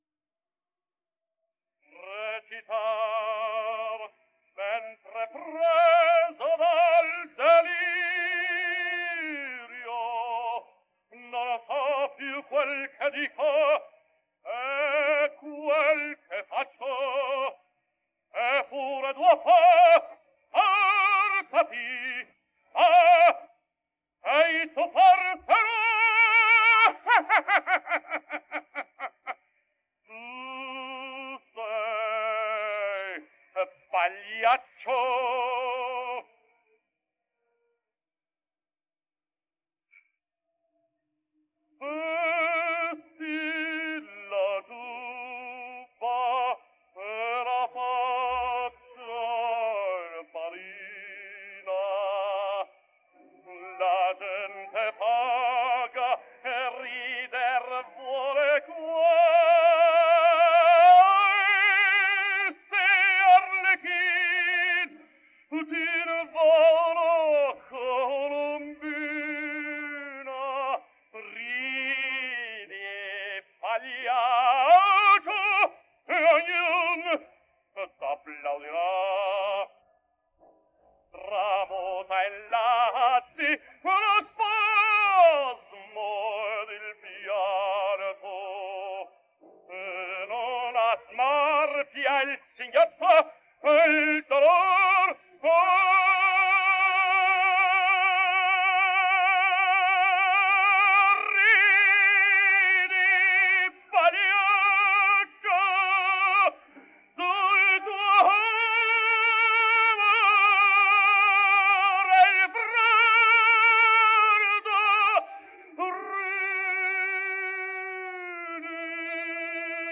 Index of /publications/papers/dafx-babe2/media/restored_recordings/caruso_giubba
denoised_vocals.wav